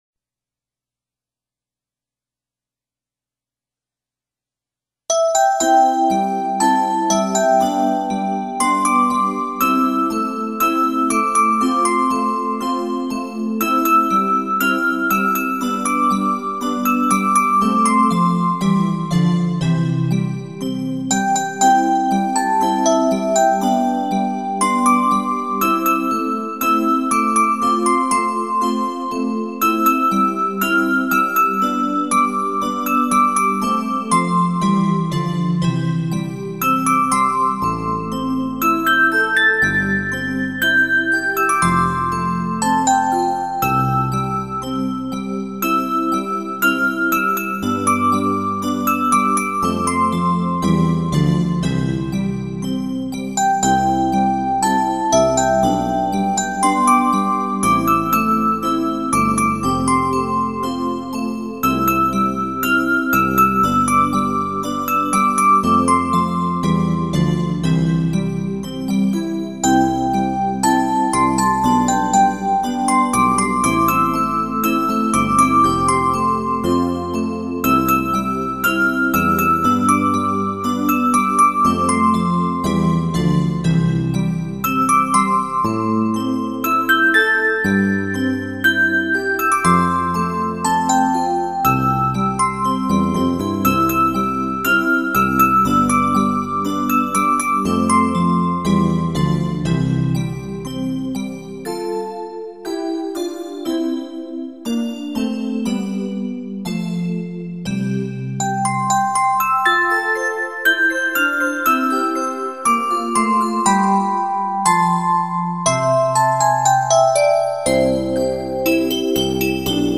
【纯音乐】
晶莹剔透的舒畅感，更是令人心醉，
水晶音乐，带给您无限遐思......